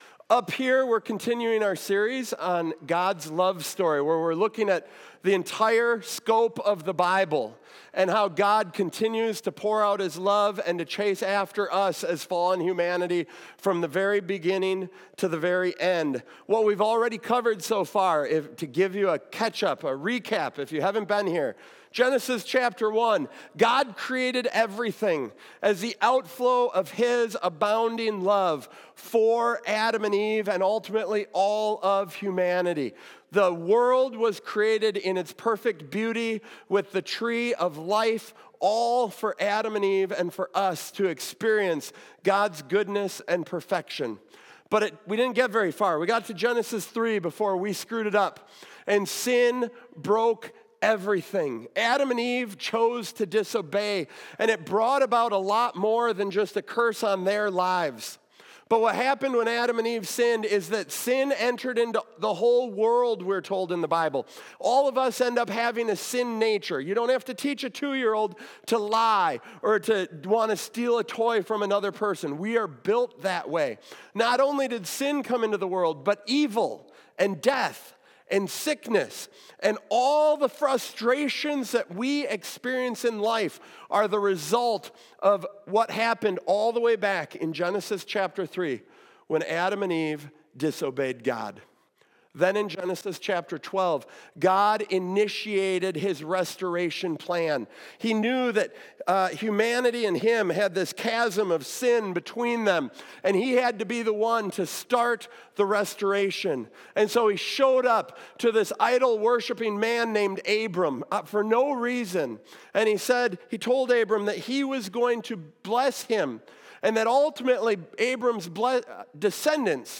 Theology God's Love Story Exodus Watch Listen Save Ever wonder why God gave the Israelites so many rules after rescuing them from Egypt? In this powerful sermon, we explore how God transformed a grumbling group of ex-slaves into His holy nation—and what that means for us today.